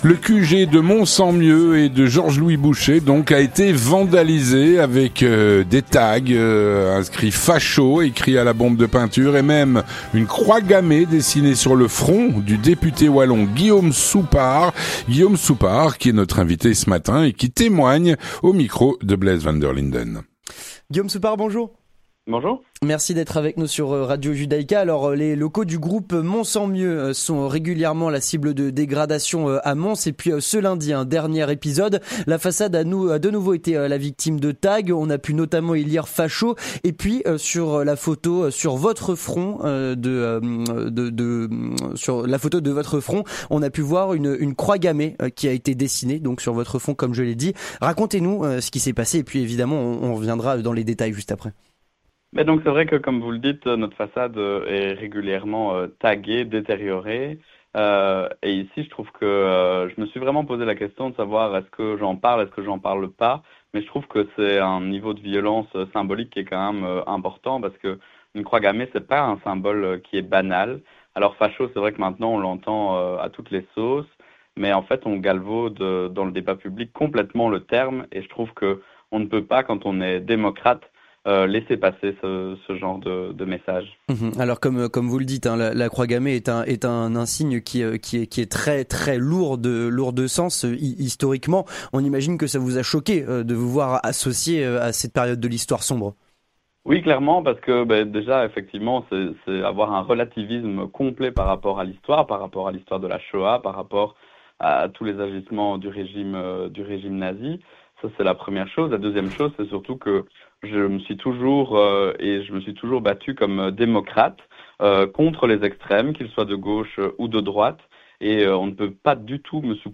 Guillaume Soupart qui est notre invité, ce matin, et qui témoigne.